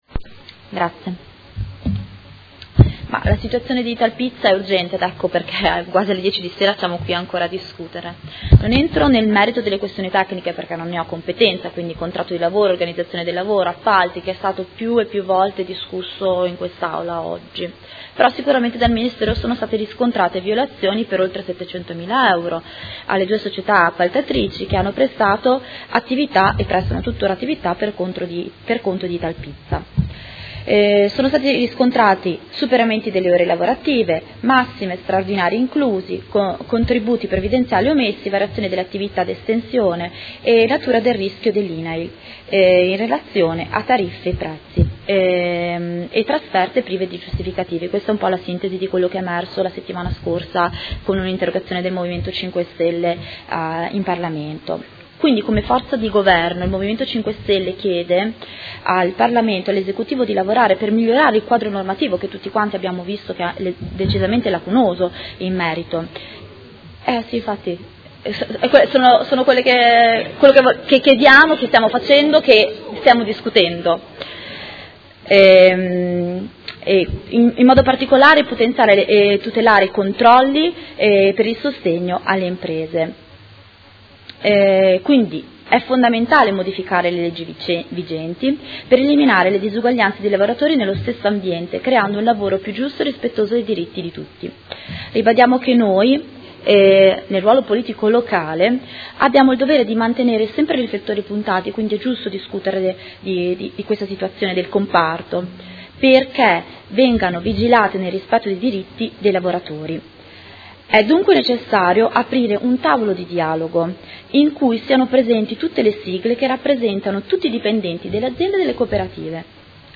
Dibattito su interrogazione, mozioni ed emendamenti riguardanti la situazione Società Italpizza S.p.A